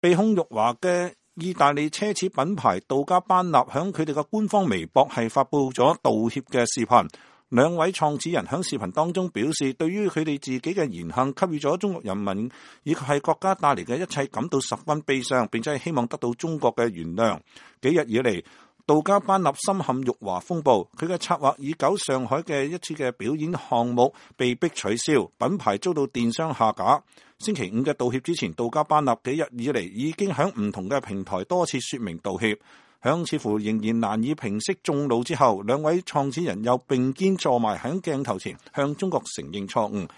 杜嘉班納兩創始人道歉 齊聲用中文說“對不起”
在全部配上中文的視頻道歉中，嘉班納和杜爾切說，在過去的幾天，他們認真地反省，對於自己的言行給中國人民及國家帶來的一切，“我們感到十分悲傷” ，並表示“面對我們在文化上理解的偏差，希望得到你們的原諒”。
最後，他們“發自內心請求得到你們的原諒”，並用中文齊聲說“對不起”。